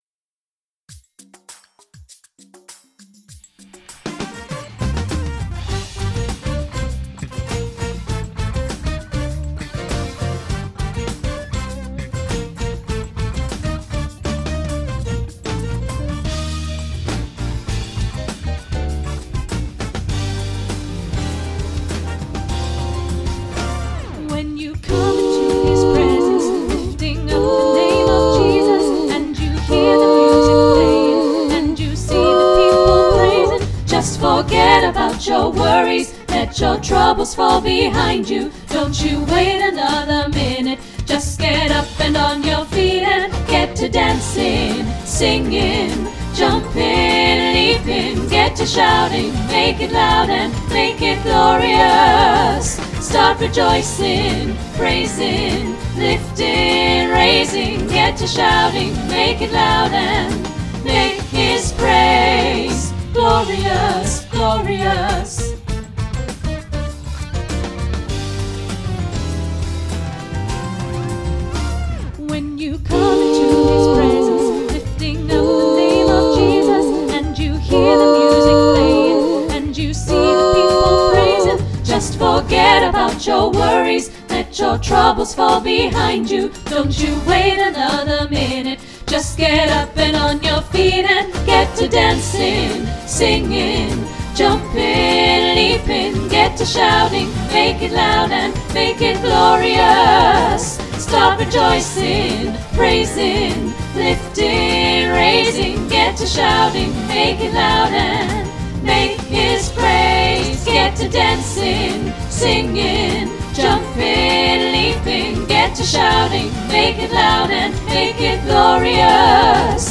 Glorious-SATB.mp3